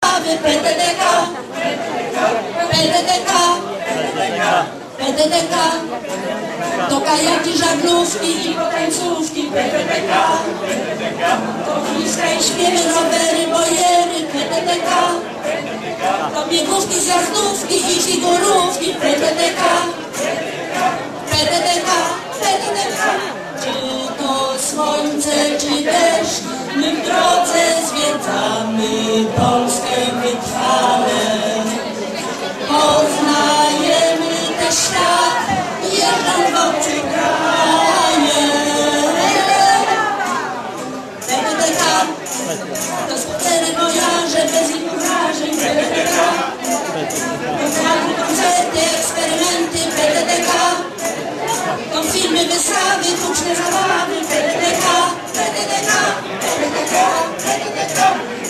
Poniżej odnośniki z fragmentami występu.